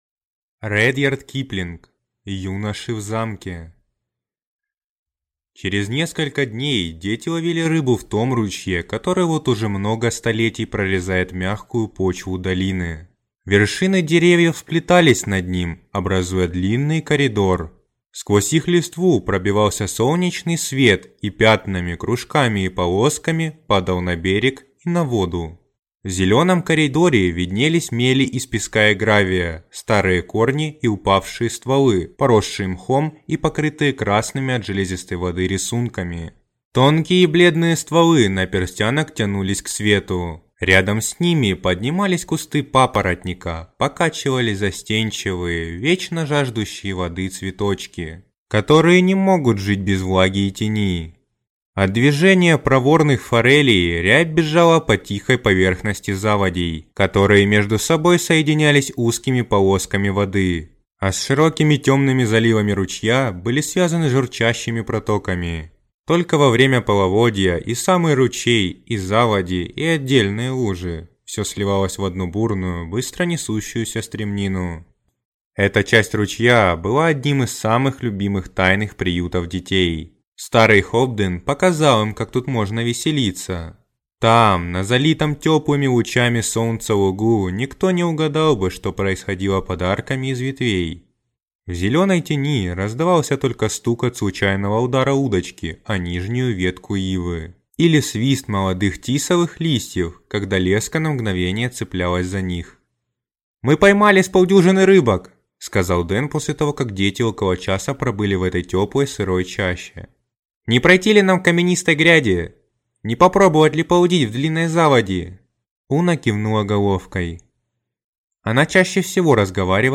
Аудиокнига Юноши в замке | Библиотека аудиокниг